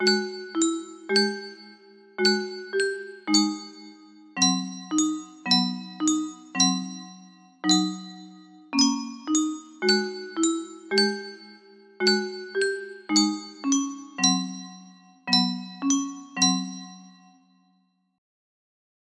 004 Dark music box melody
Full range 60